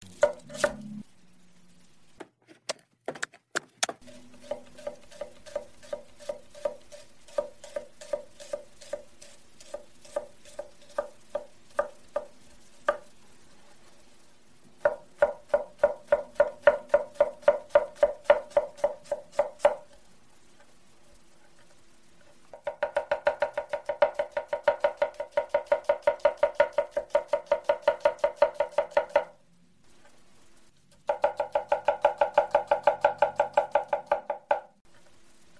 Tiếng Xắt, Thái… Hành, Rau củ quả… chuyên nghiệp trên thớt
Thể loại: Tiếng ăn uống
tieng-xat-thai-hanh-rau-cu-qua-chuyen-nghiep-tren-thot-www_tiengdong_com.mp3